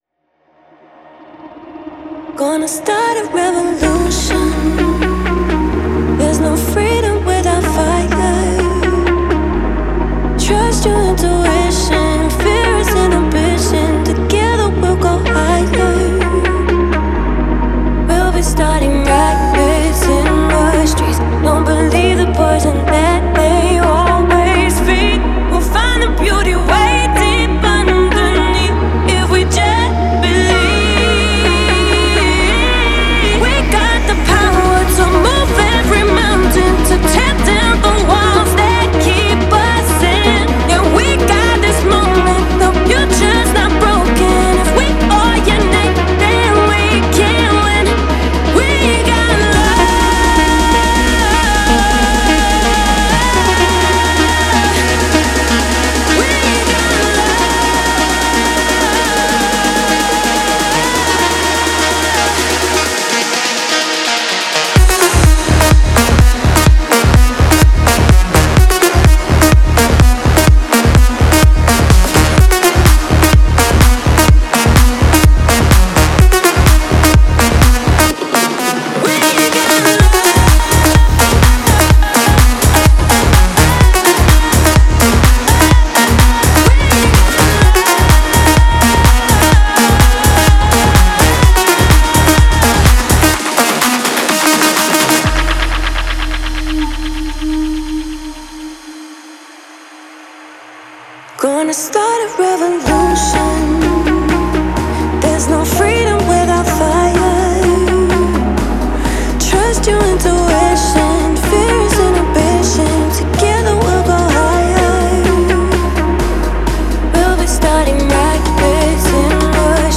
это яркая и энергичная композиция в жанре EDM